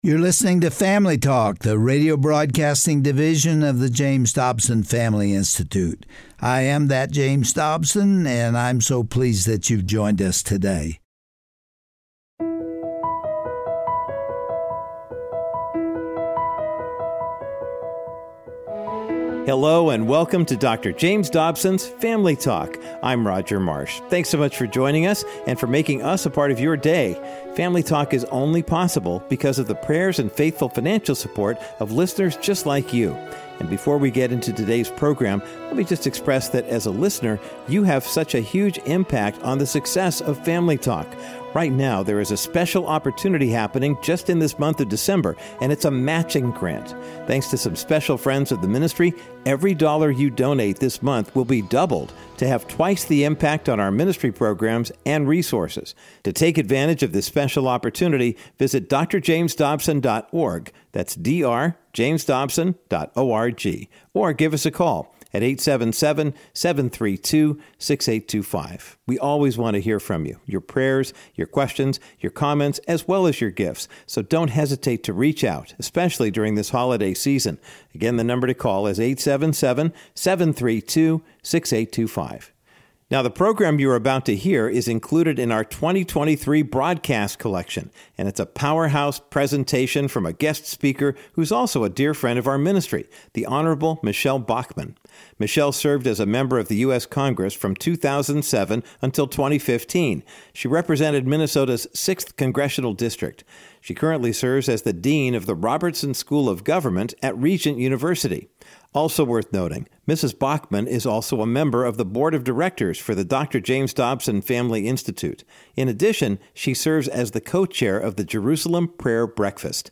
Host Dr. James Dobson
Guest(s):The Hon. Michele Bachmann